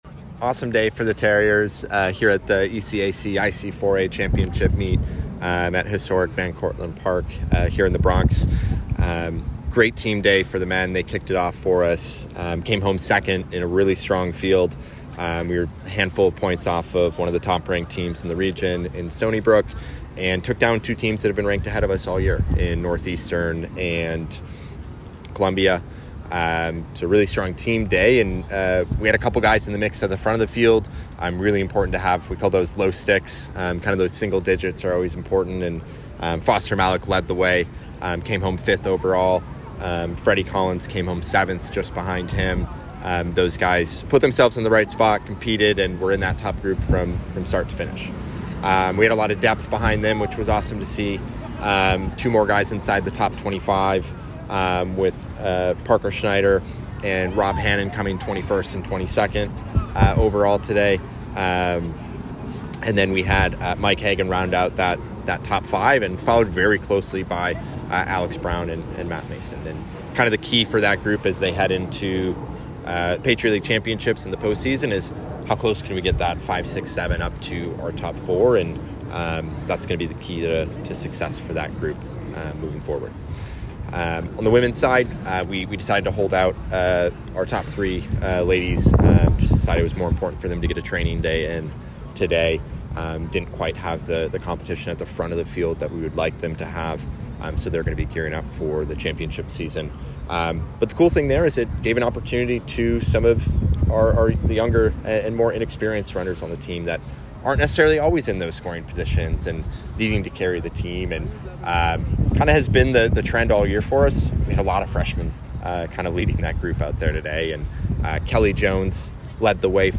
IC4A-ECAC Postrace Interview